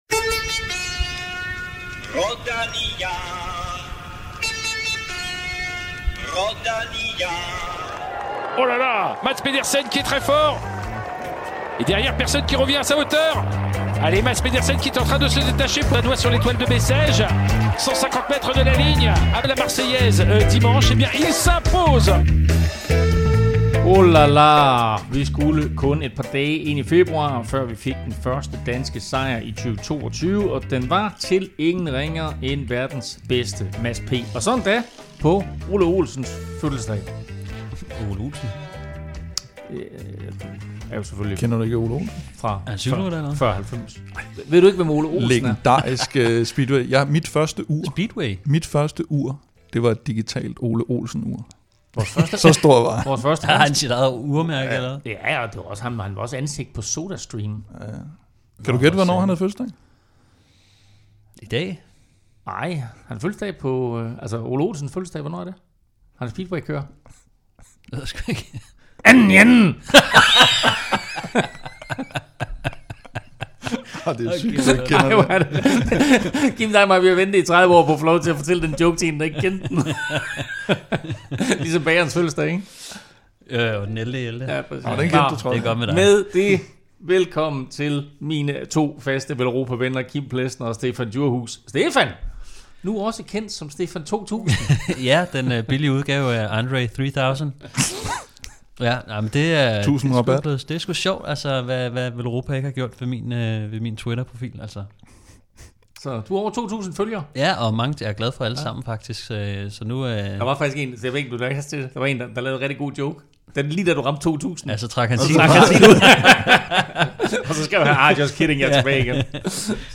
Hør et dugfriskt interview med Årets Cykelrytter 2021, der blandt andet har et godt træningstip og forsikrer, at han ikke skal give sin cykel til Primoz Roglic i år. Vi taler også om Remcos grusproblemer, stærke supersprintere og så er der selvfølgelig quiz.